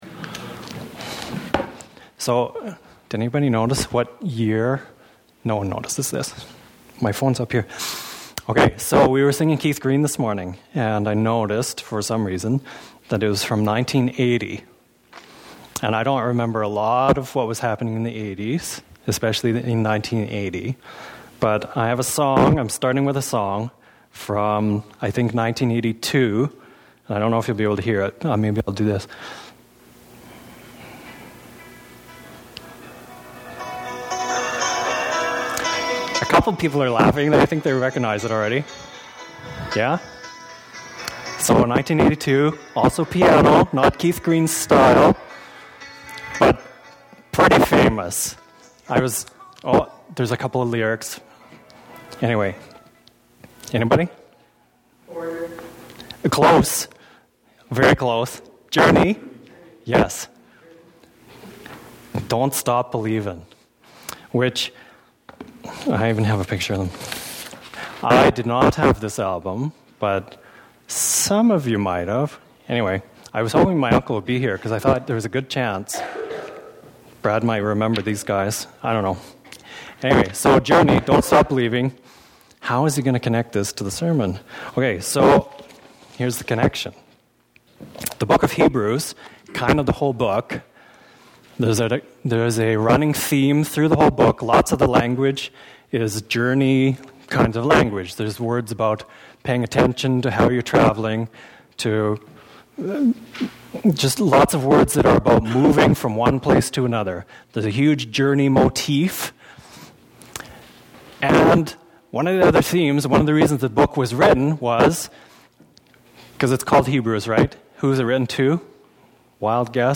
Sadly, due to recording with a potato set at the wrong decibels, the sound is poor.